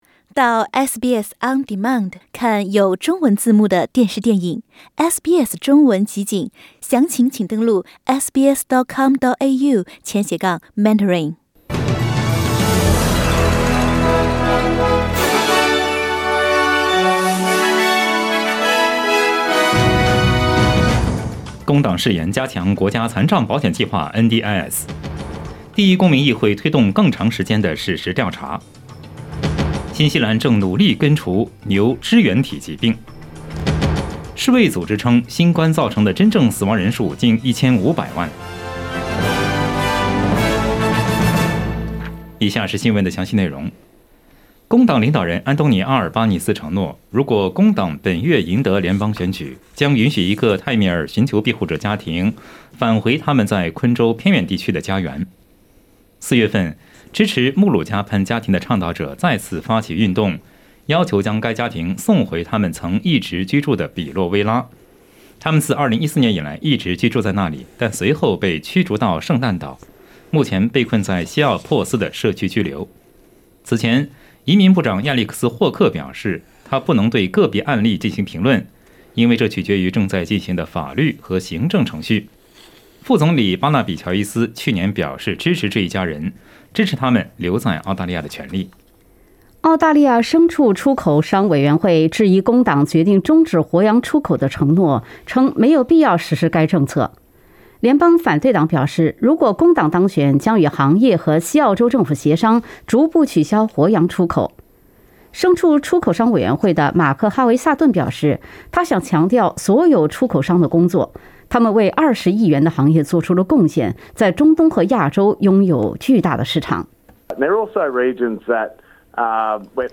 SBS早新闻（5月6日）
SBS Mandarin morning news Source: Getty Images